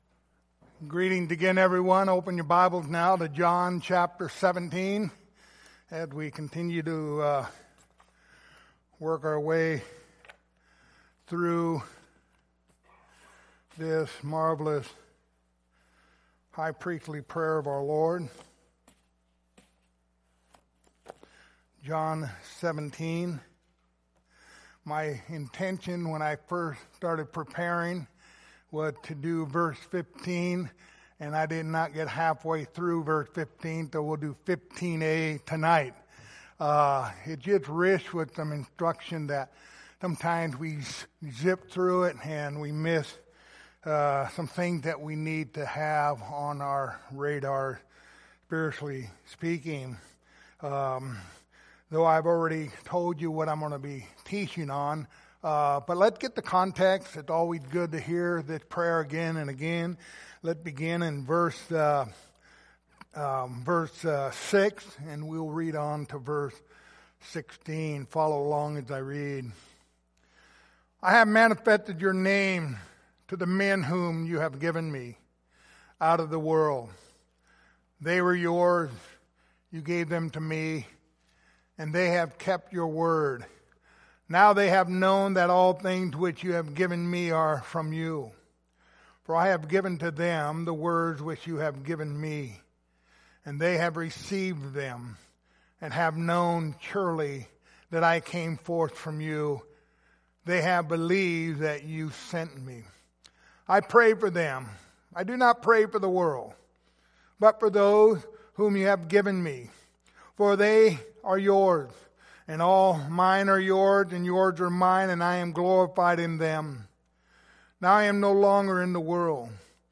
Lord’s Supper, First Baptist Church
Service Type: Lord's Supper